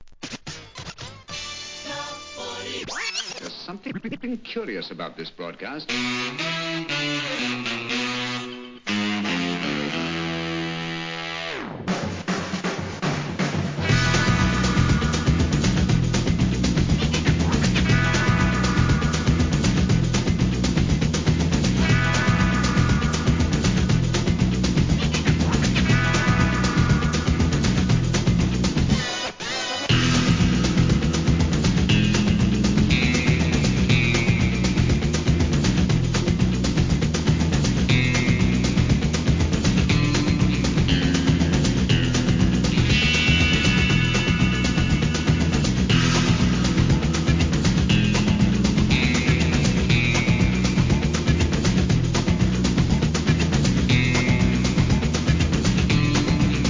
映画サントラを思わせるFUNKYブレイクビーツ!!